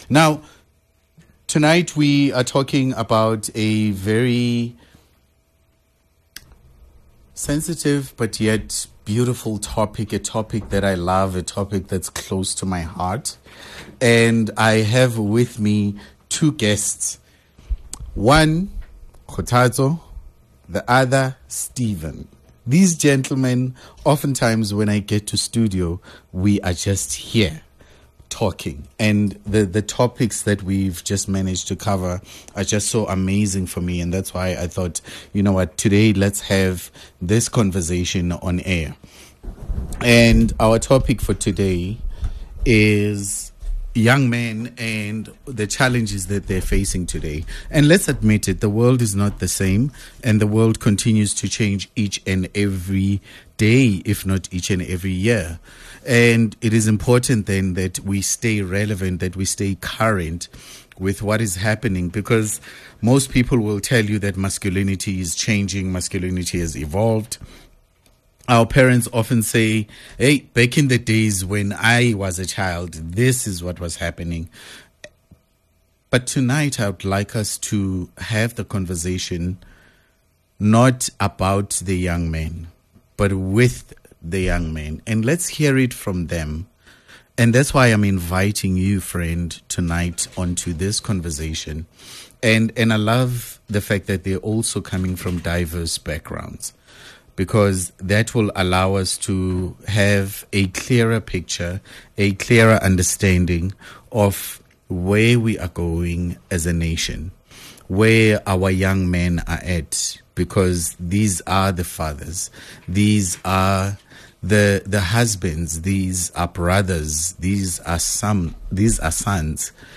This episode offers honest conversations and valuable insights.